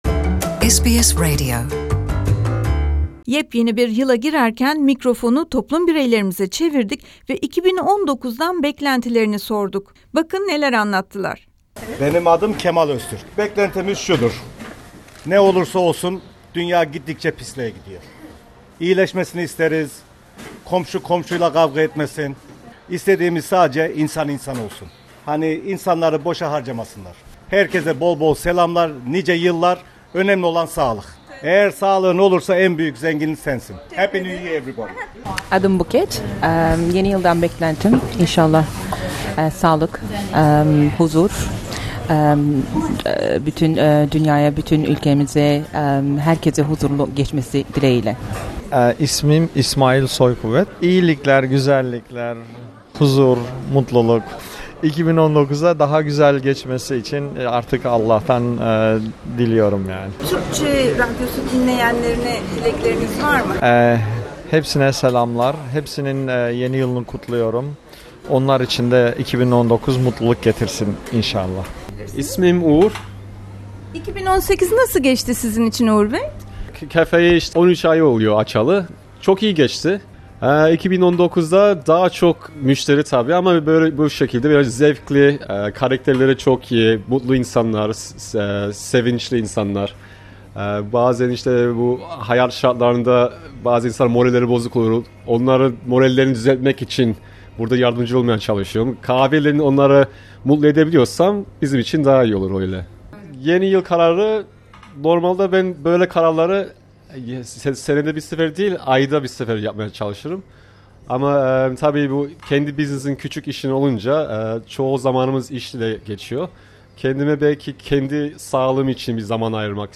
Avustralya Türk toplumu bireyleri, mikrofonumuza 2019'dan beklentilerini, yeni yıl dileklerini ve yeni yıl için aldıkları kararları anlatıyor...